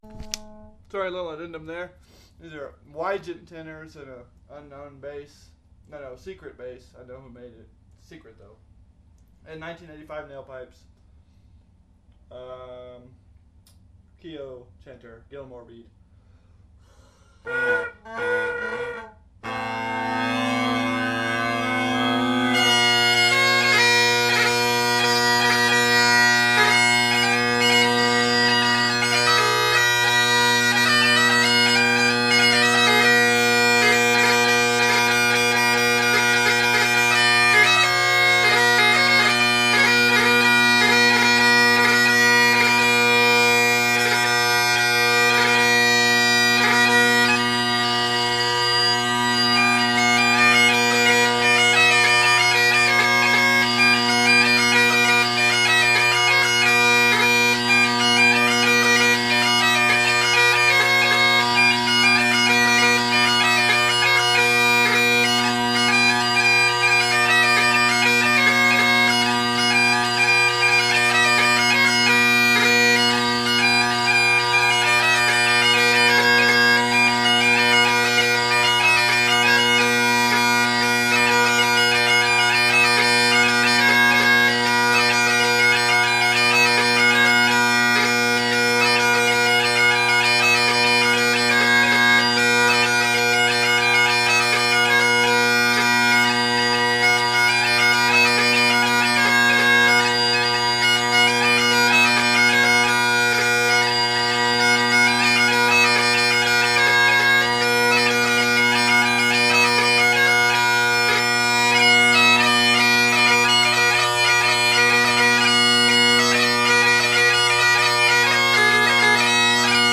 Great Highland Bagpipe Solo
Here I have for your listening endurement (I sorta messed up the recording [a bit drone heavy], I think the bass drone was sitting right in front of the mic) enjoyment some 4 parted 6/8 jigs.
P.S. Wygent tenor drone reeds.
jigs.mp3